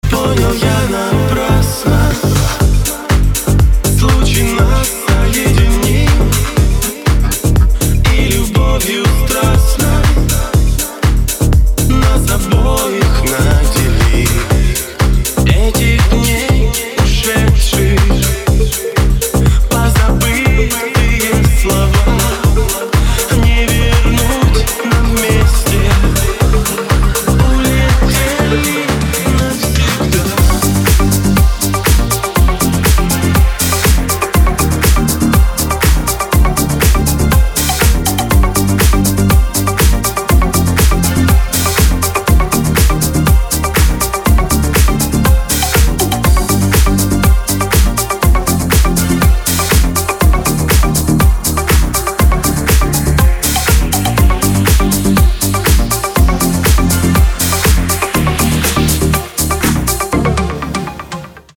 • Качество: 256, Stereo
мужской вокал
dance
Club House
электронная музыка
club